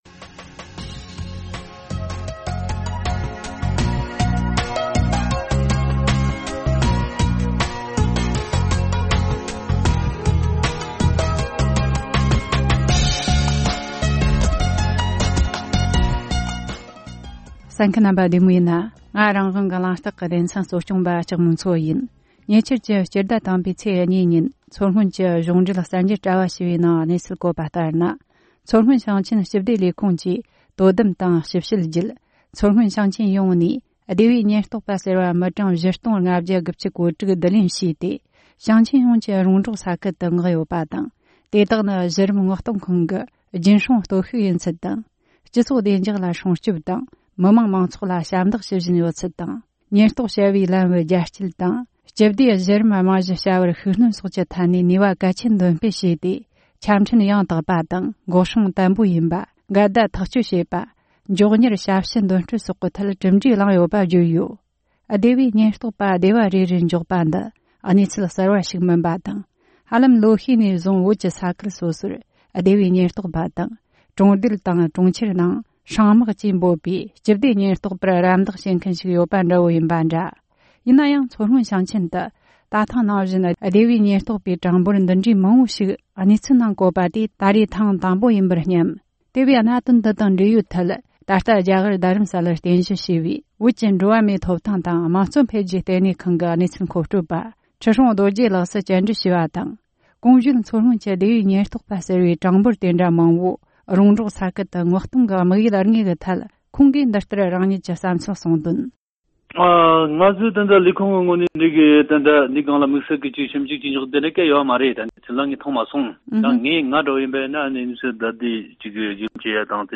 གླེང་མོལ་བྱས་བར་གསན་རོགས་གནོངས།།